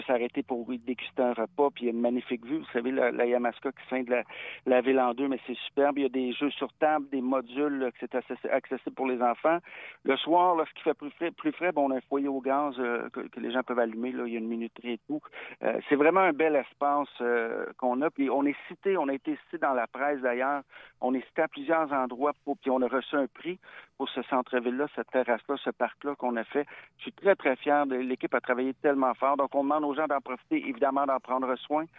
Le maire de l’endroit, Patrick Melchior en a parlé sur nos ondes mardi matin